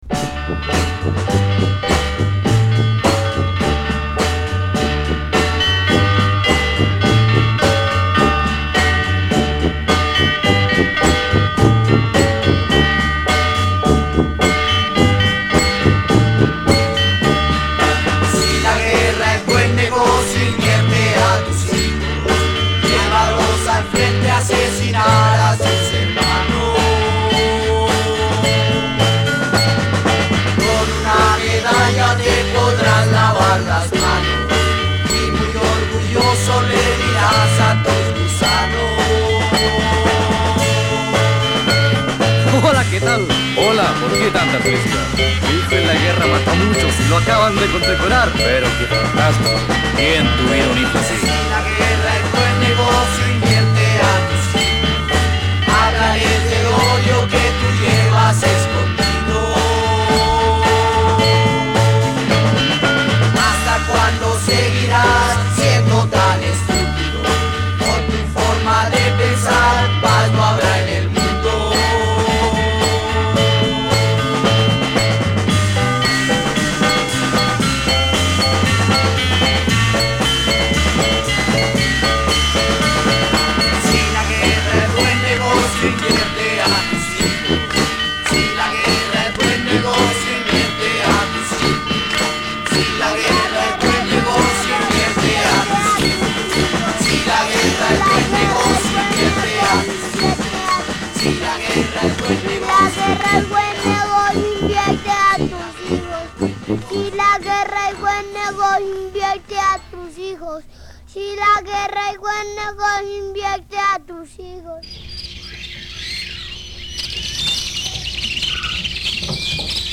Published September 15, 2009 Garage/Rock Comment